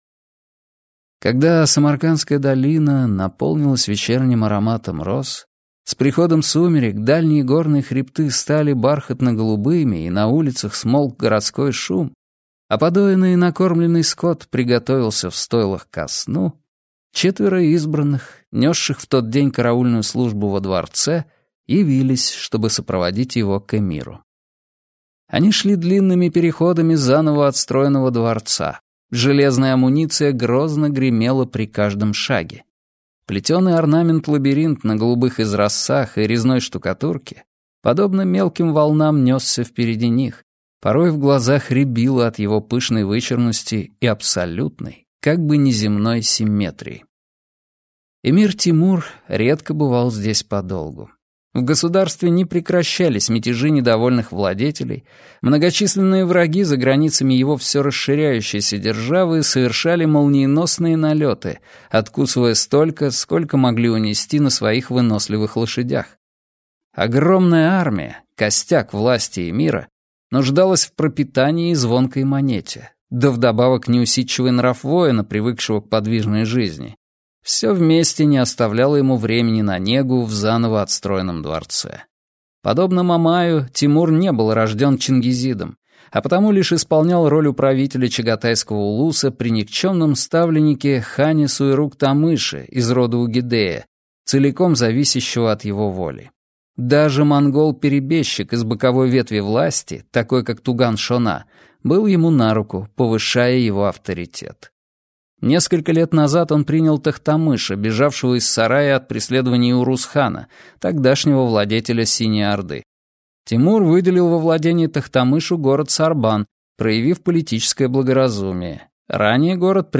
Аудиокнига Крепость | Библиотека аудиокниг